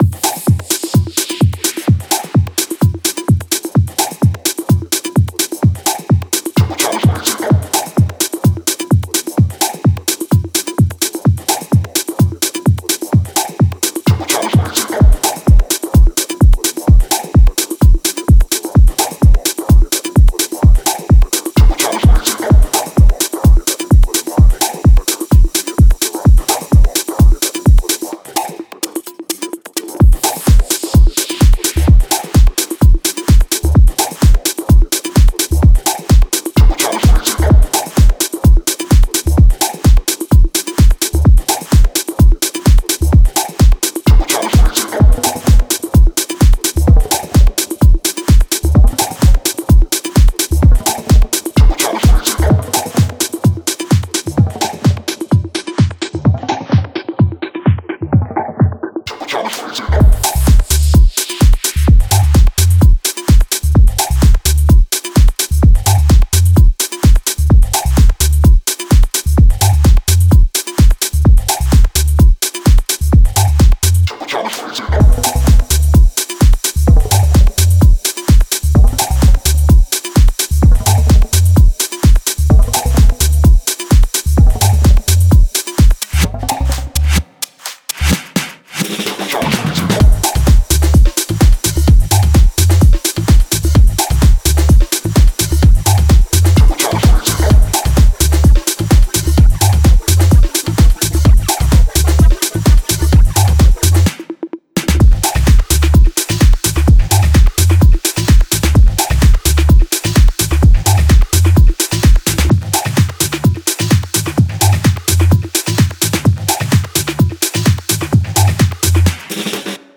Genre: House.